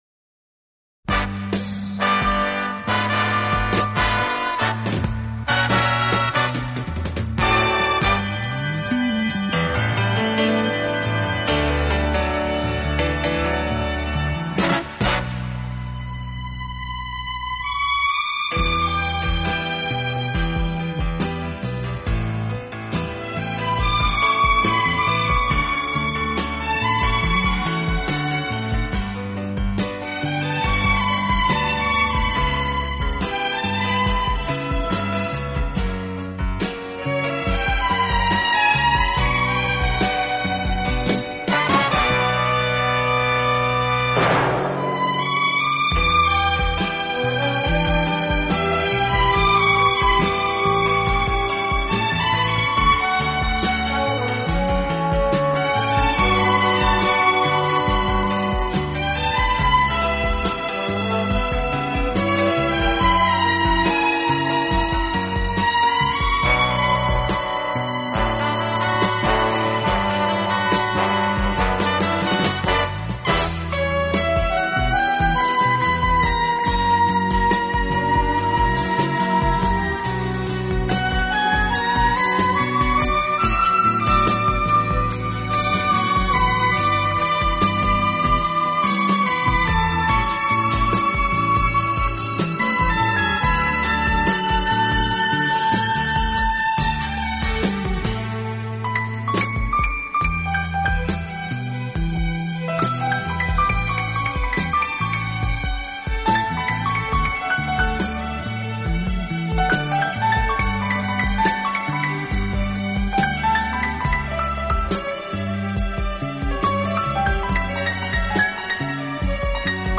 浪漫的弦乐音响，加上恰当
好处的管乐组合，给人以美不胜收之感。